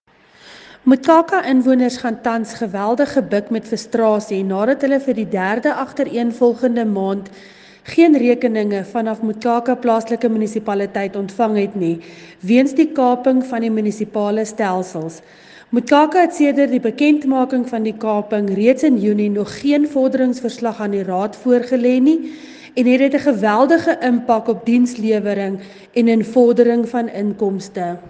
Afrikaans soundbites by Cllr Linda Louwrens and